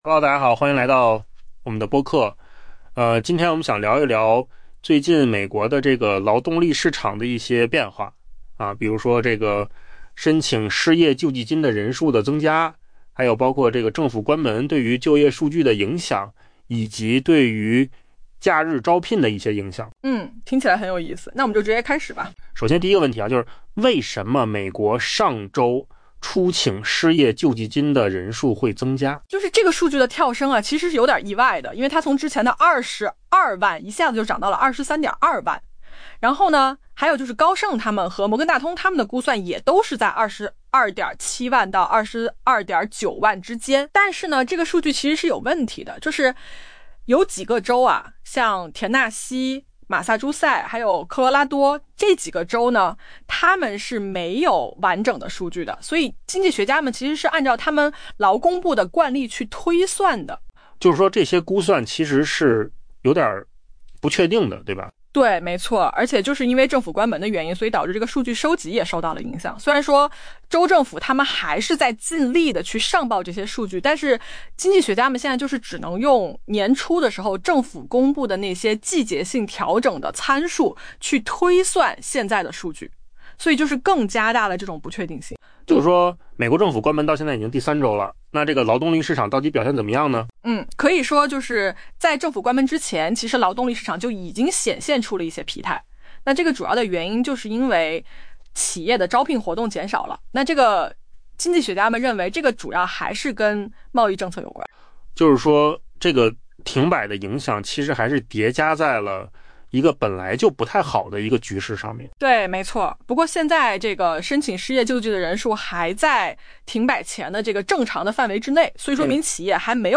AI 播客：换个方式听新闻 下载 mp3 音频由扣子空间生成 经济学家周四的测算显示，上周初请失业救济金的美国人意外增多，且随着劳动力市场景气度松动，进入 10 月以来，领取失业金的人数也在持续攀升。